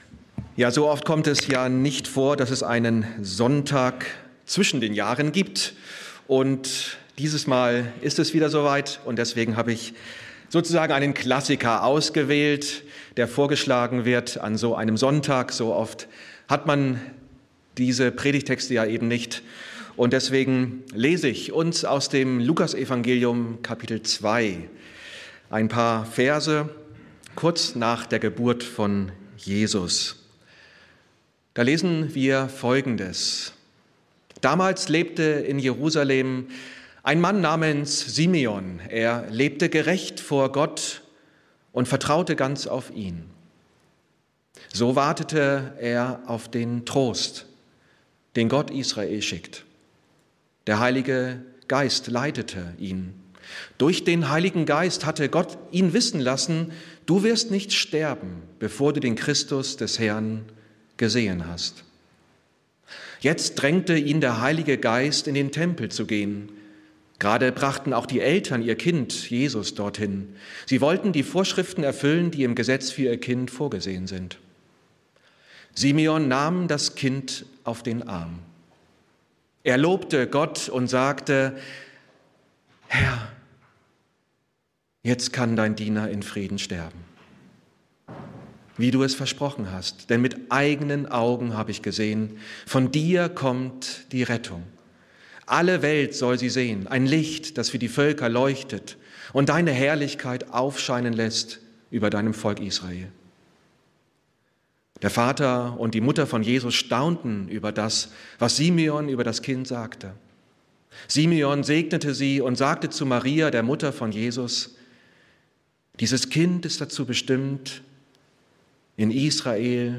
Predigten aus der Gemeinde in Bietigheim